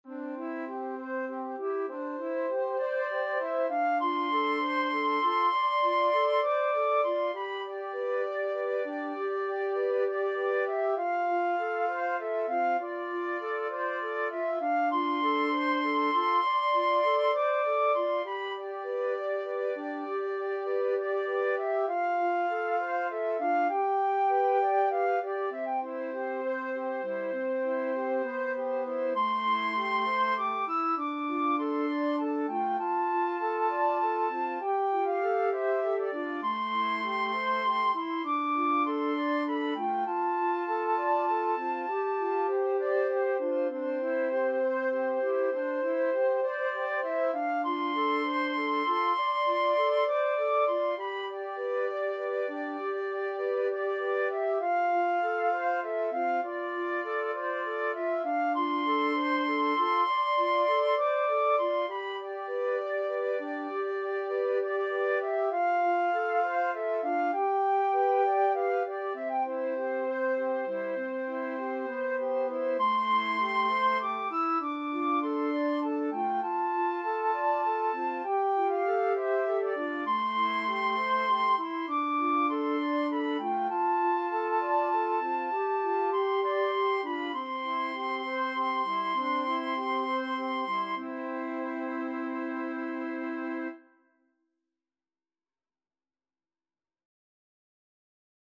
Flute 1Flute 2Alto Flute
6/8 (View more 6/8 Music)
Classical (View more Classical Flute Trio Music)